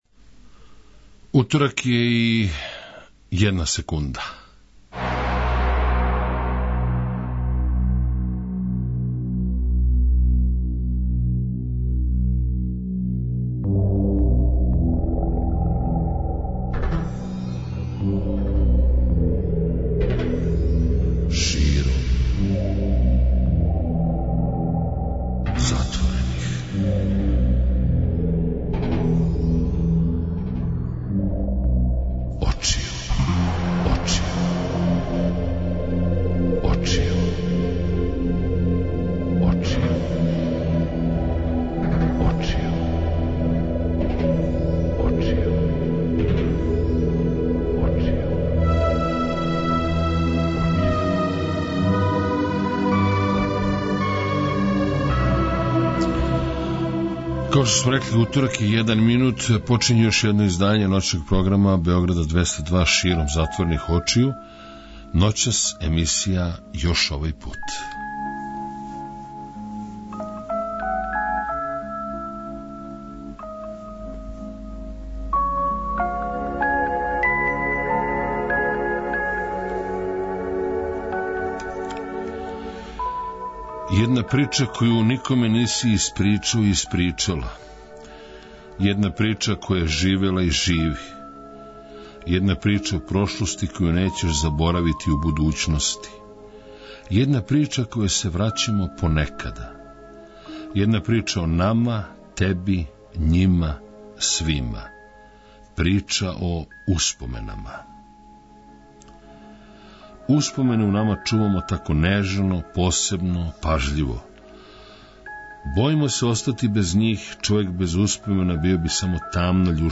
Вечерас, у емисији Још Овај Пут, ноћног програма Београда 202 УСПОМЕНЕ.